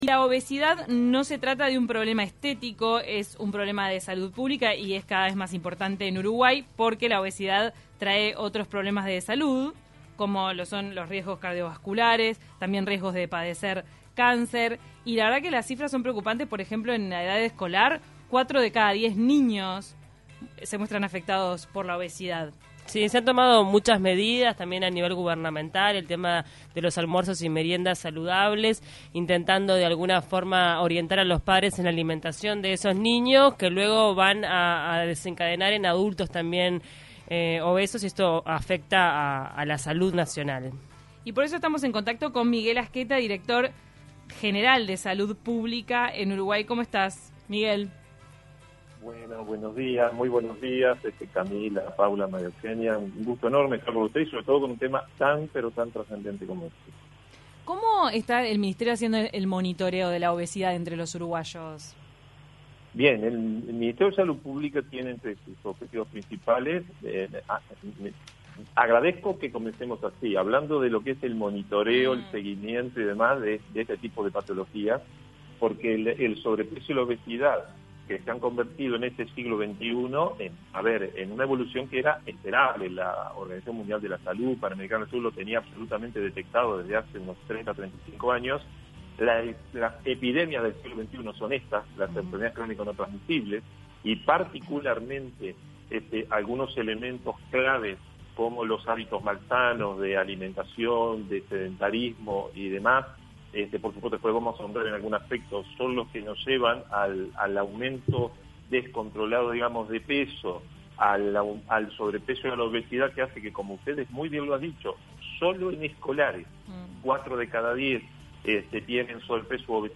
En el día mundial de la obesidad dialogamos con Miguel Asqueta, Director del Ministerio de Salud Pública respecto a la enfermedad no transmitible en Uruguay.